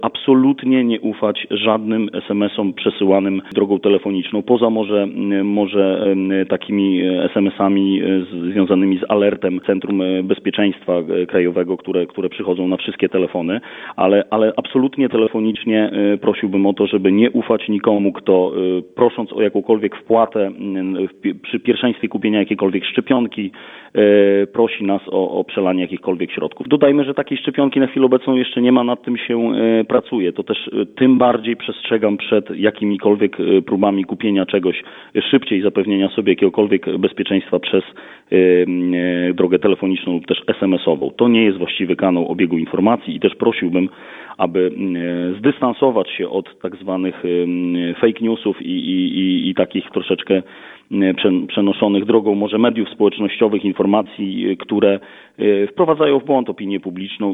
O rozwagę, rozsadek, odpowiedzialność i unikanie paniki apeluje podinspektor Bartosz Lorenc, Komendant Miejski Policji w Suwałkach.